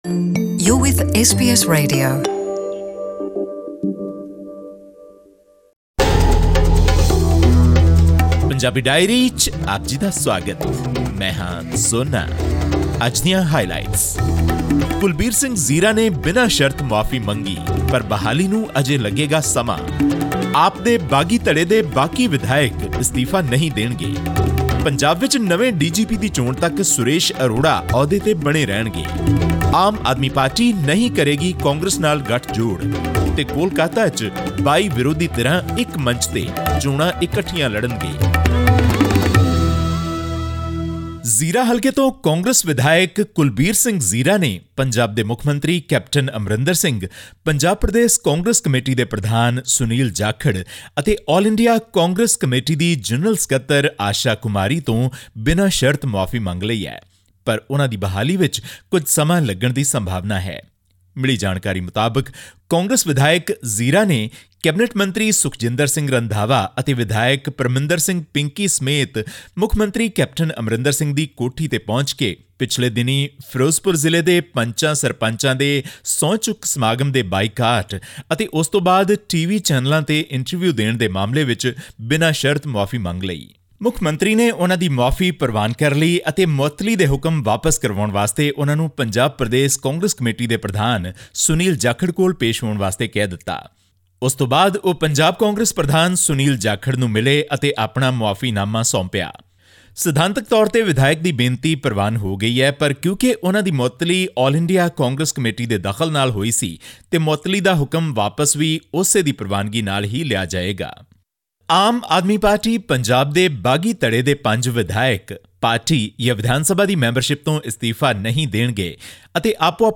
Here's the weekly wrap of all the important news from Punjab.
Our Punjab correspondent brings to you the details of this development from Punjab, along with other important news.